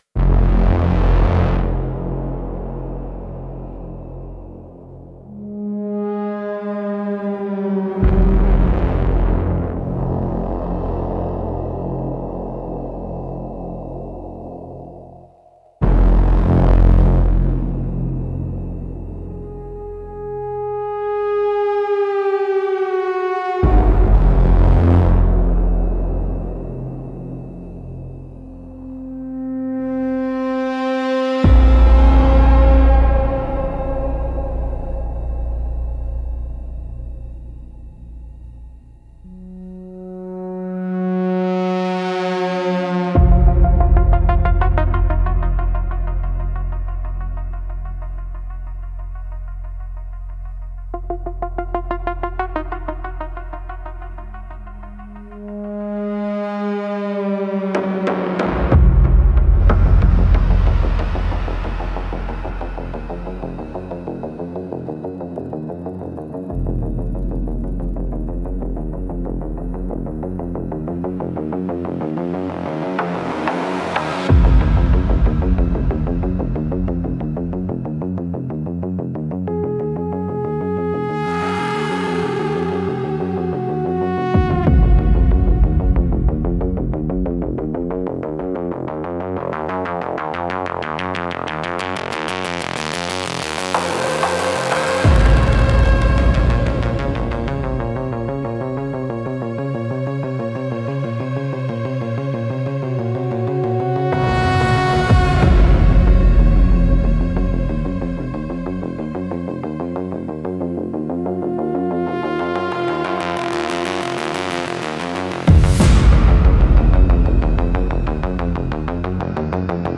Instrumental - Echoes in the Grid 3.49